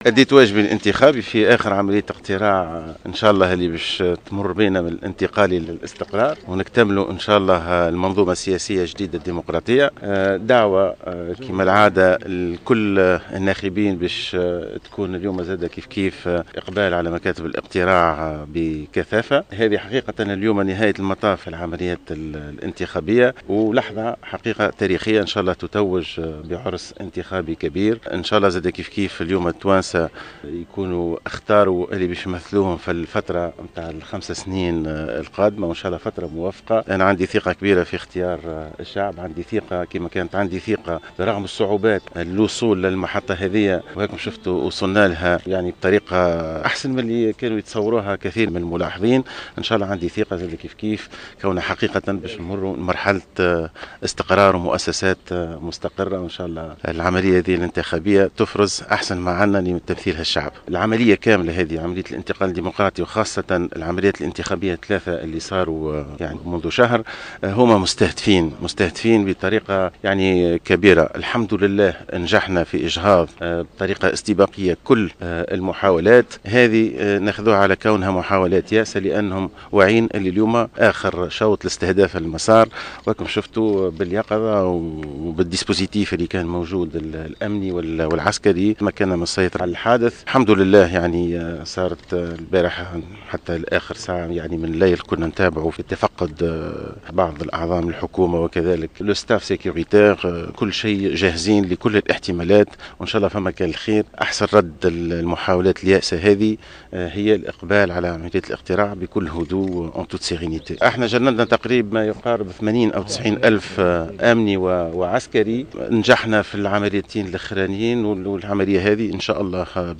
أكّد رئيس الحكومة المؤقتة مهدي جمعة أثناء أداء واجبة الإنتخابي صبيحة اليوم أن تونس تعيش لحظات تاريخية و نقطة تحوّل من المؤقت إلى الدائم و المستقرّ مثمّنا المجهودات المبذولة من جميع الأطراف لإنجاح المسار الإنتخابي .